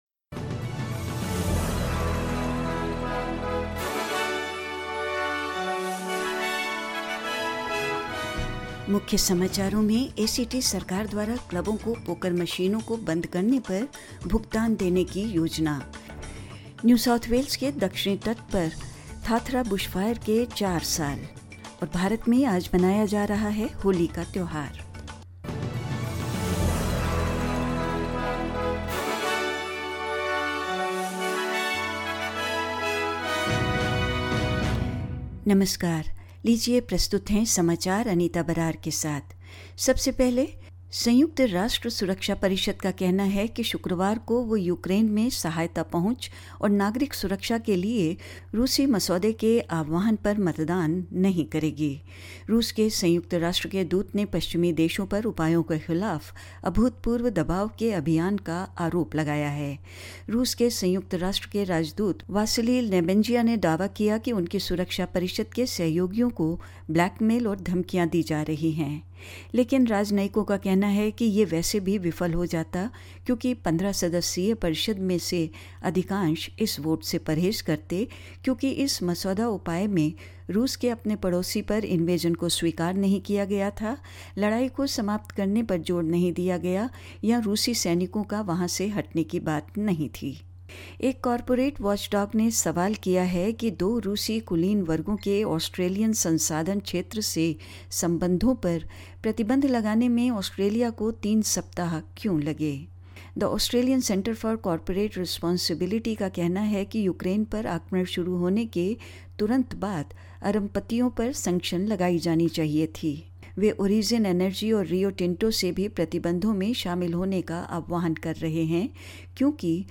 In this latest SBS Hindi bulletin: The ACT government announce plans to pay clubs to give up their poker machines; Towns on the New South Wales south coast mark four years since the devastating Tathra bushfires; India is celebrating today the festival of colour; Rafael Nadal has beaten Australian Nick Krygios in a drama filled quarter final match at the Indian Wells Masters and more news.